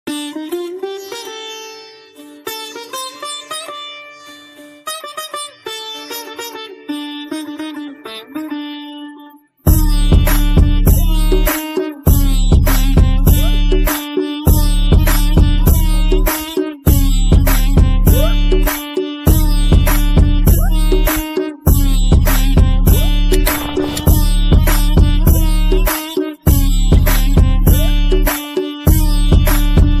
Impressive Background Music for High-Quality Videos